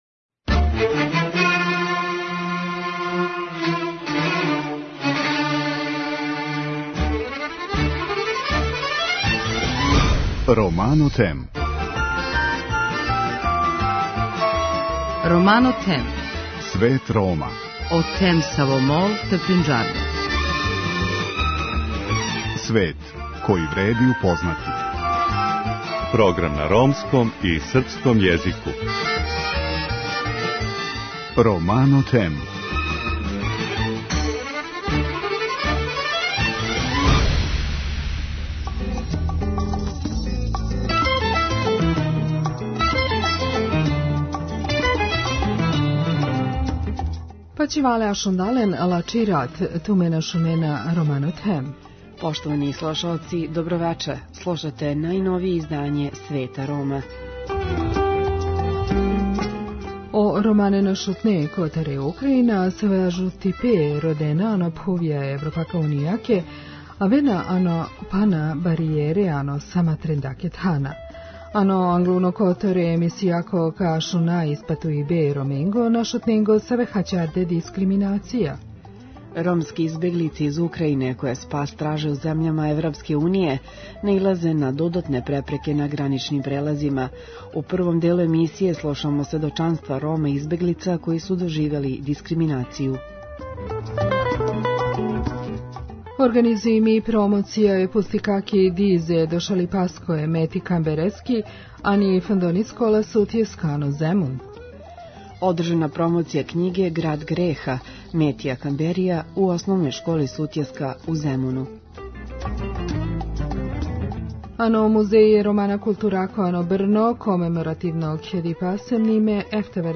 Ромске избеглице из Украјине, које спас траже у земљама ЕУ, наилазе на додатне препреке на граничним прелазима. У првом делу емисије чућете њихова сведочења.
У наставку, очекује вас извештај из Музеја ромске културе у Брну где је, комеморативним скупом, обележено 79 година од првог масовног транспорта Рома и Синта током Холокауста.